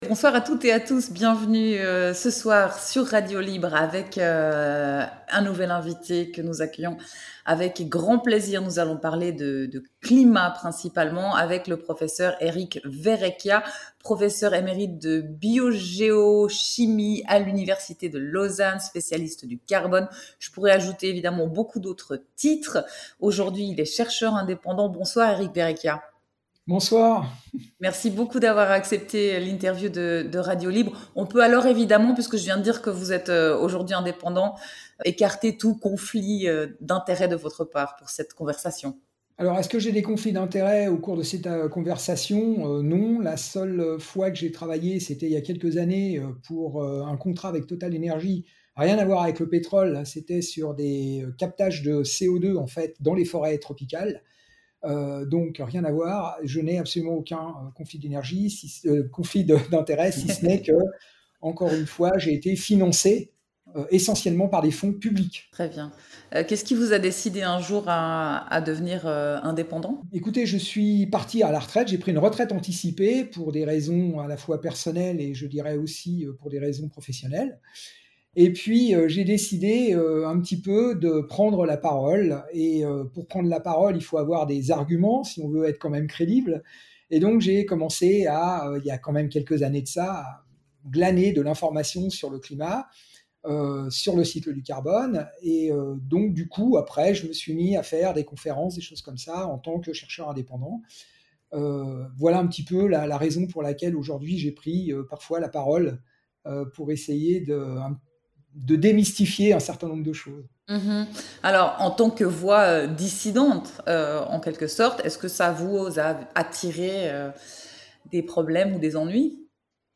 19h à 20h : Émission publique sur le stream du site et dans l’app mobile android.